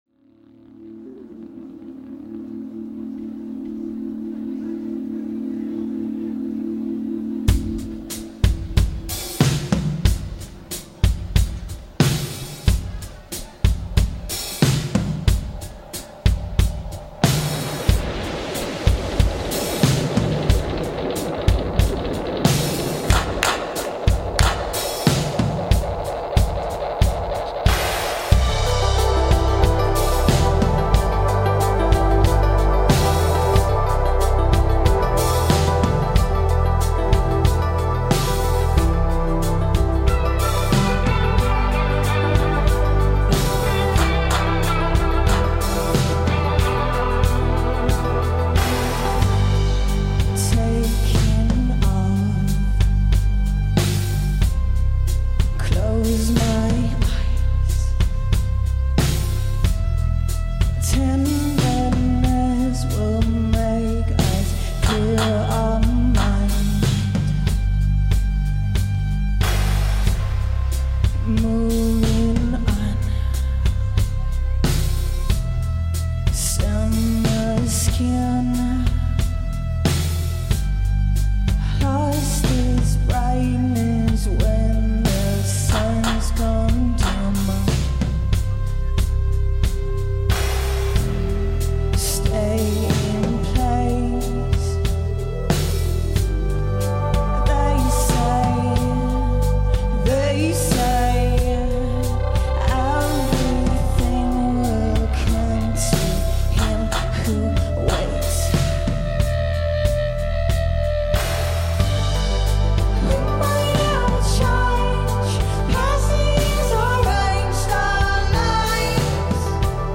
is a Dutch singer-songwriter, fronting a four piece group
a hypnotic adventure through Techno and 80s dreamscape.